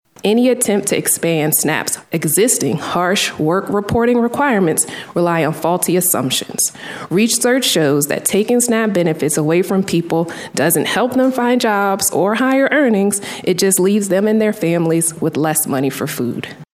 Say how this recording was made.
Partisan differences over limiting Supplemental Nutrition Assistance Program (SNAP) spending, which makes up more than 80% of the cost attributed to the federal farm bill, were on full display during a Senate Ag subcommittee hearing.